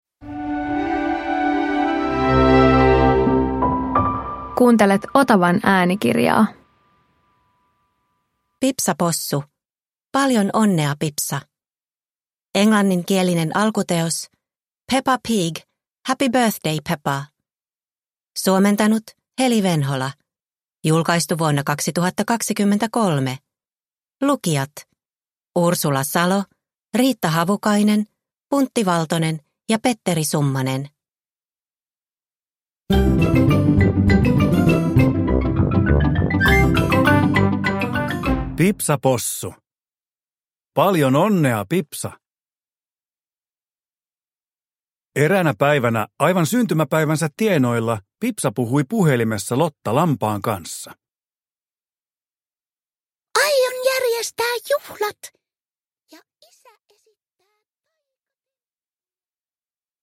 Pipsa Possu - Paljon onnea, Pipsa! – Ljudbok – Laddas ner